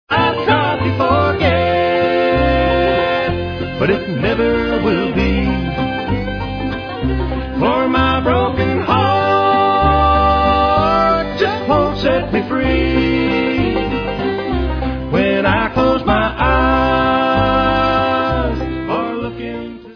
sledovat novinky v oddělení Rock/Bluegrass